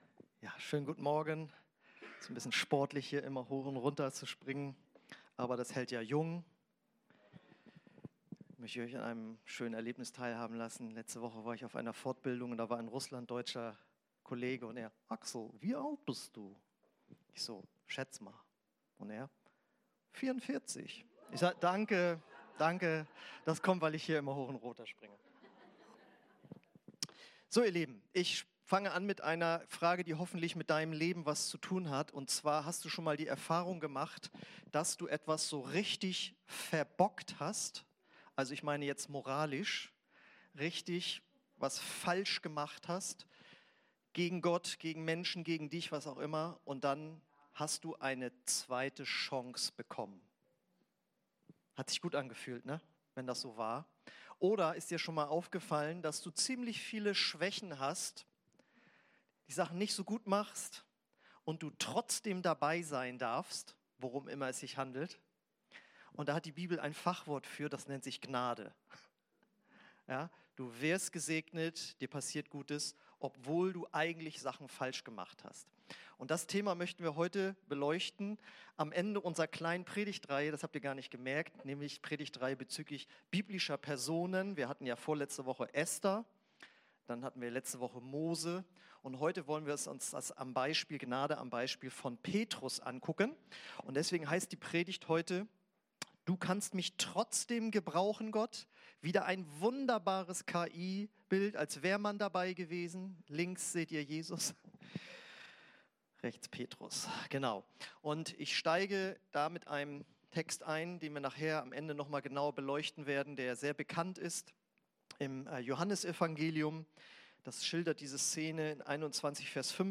Predigten (v1) – OASIS Kirche